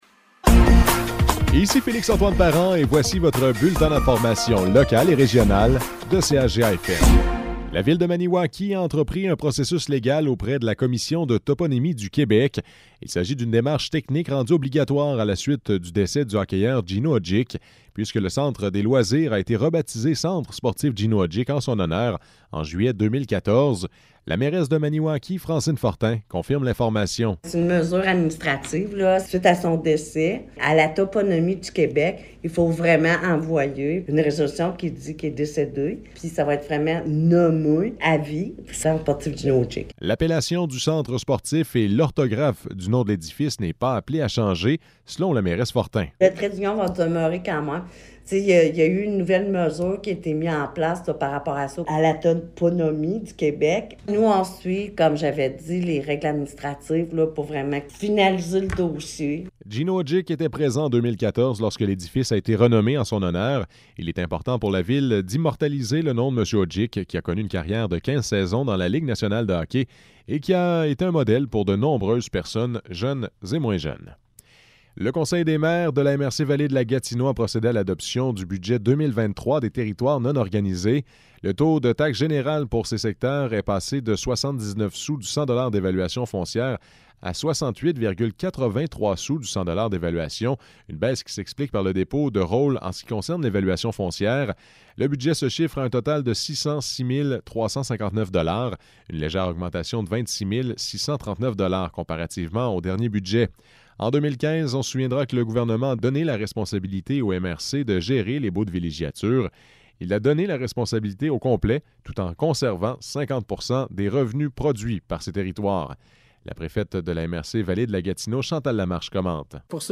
Nouvelles locales - 16 février 2023 - 12 h